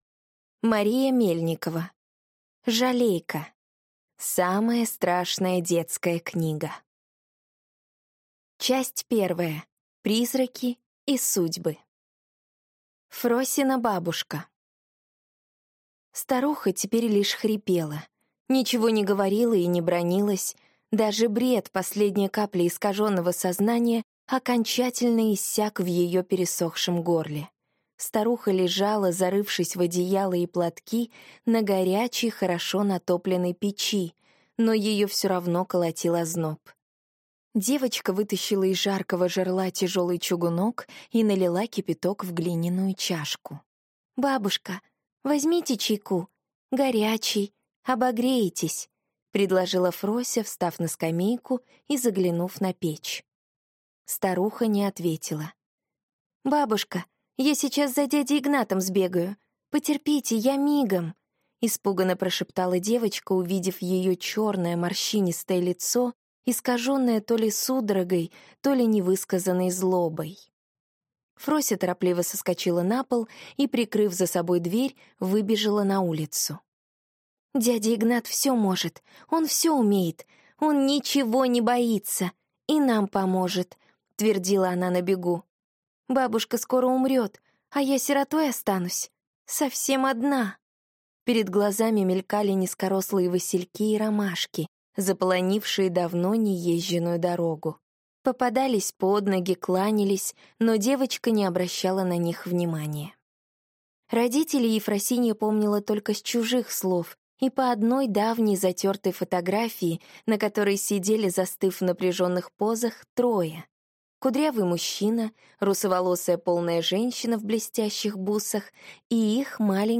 Аудиокнига Жалейка | Библиотека аудиокниг
Прослушать и бесплатно скачать фрагмент аудиокниги